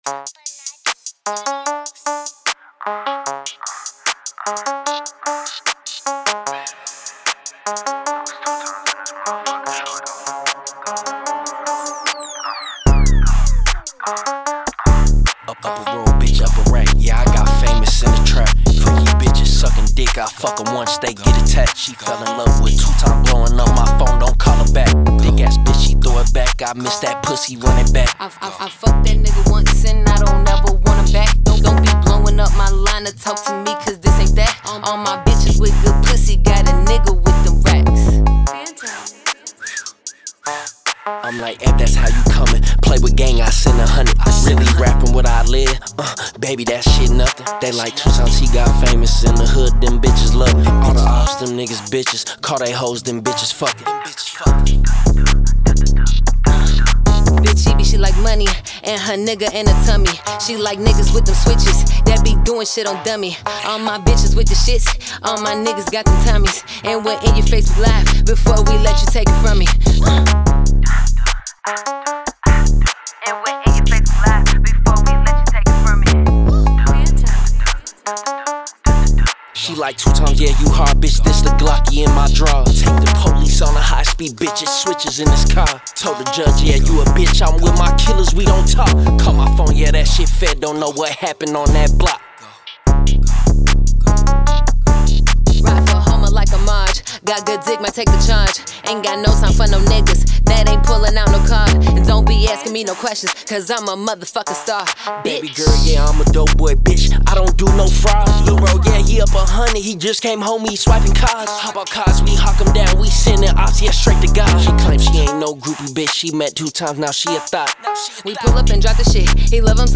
Trap
raw high energy